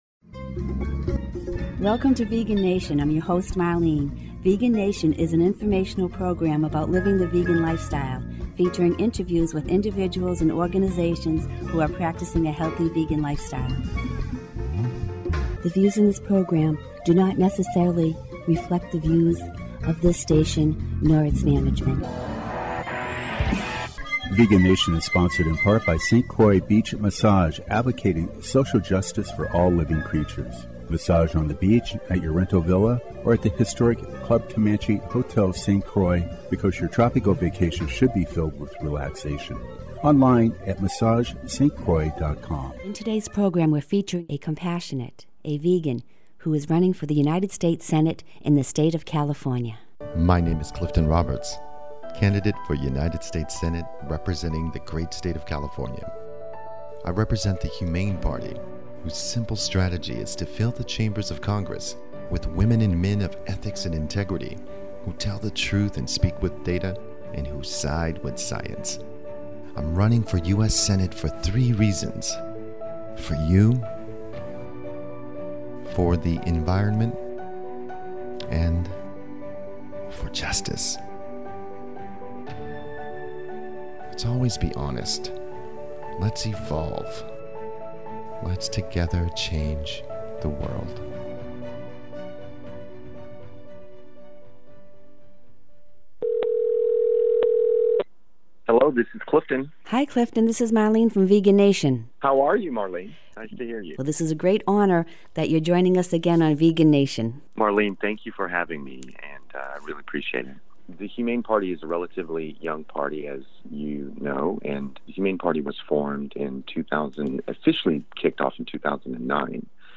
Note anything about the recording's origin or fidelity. The audio replay files posted above are the Vegan Nation shows aired in 2018.